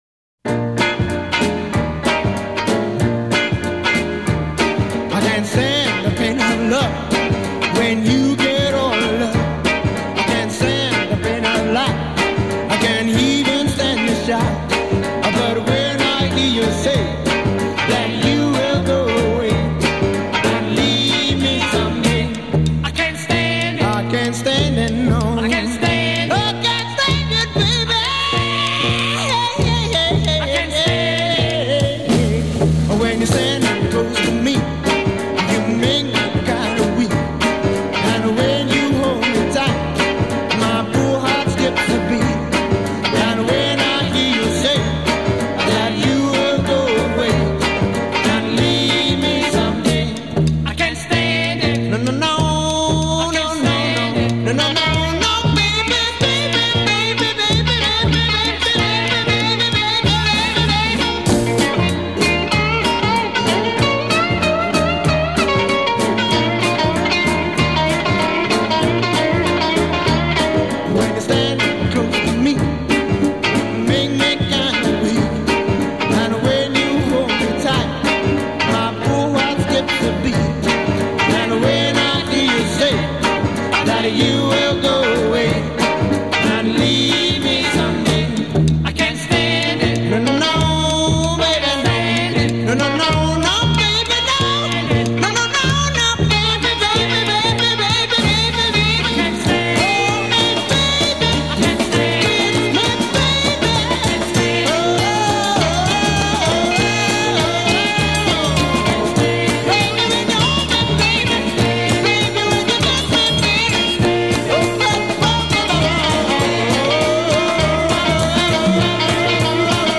Recorded at Lansdowne Studios, Holland Park, London.
lead vocal and guitar solo
rhythm guitar & backing vocals
bass
drums
Intro 0:00 4 ensemble vamp
second voice joins with harmony on second half.   a
drop drums and guitar   b
A' Verse1 1:01 8 guitar solo
Outro 1:39 24 repeat refrain, double rhythm, and fade b